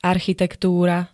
architektúra [-t-] -ry -túr ž.
Zvukové nahrávky niektorých slov